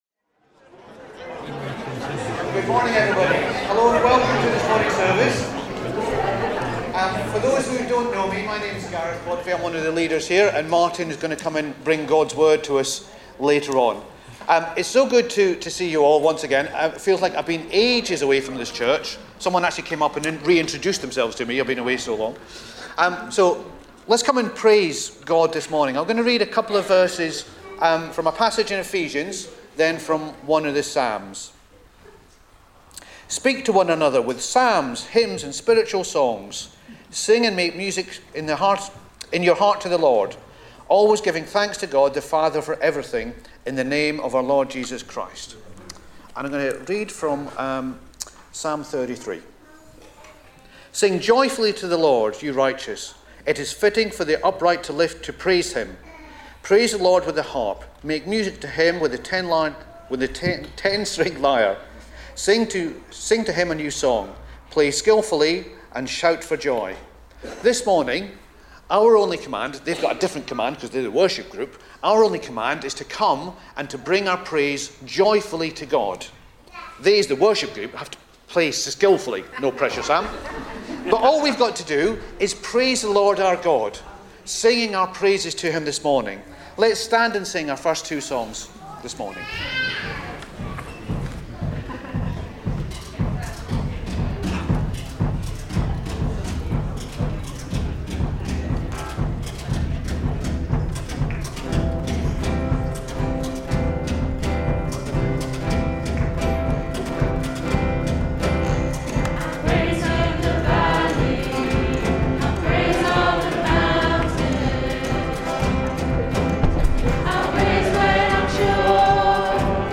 21 September 2025 – Morning Communion Service
Service Type: Morning Service